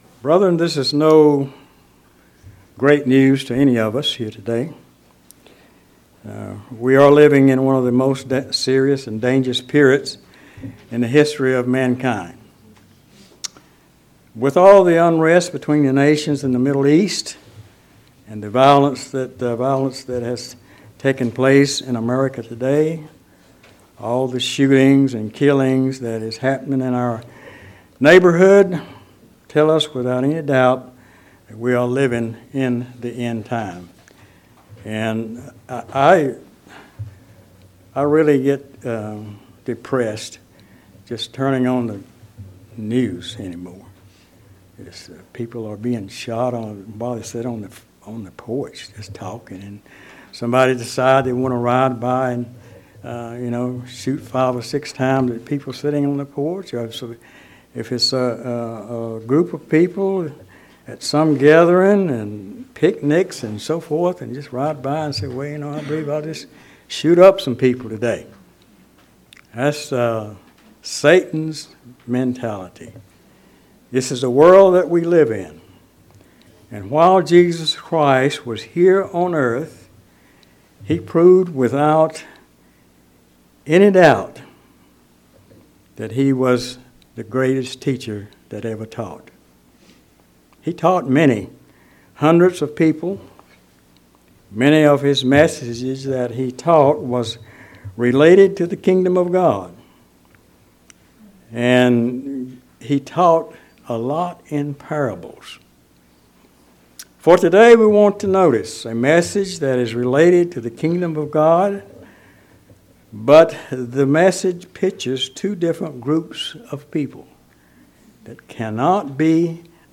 A sermon delivered to discuss the importance of spiritual readiness.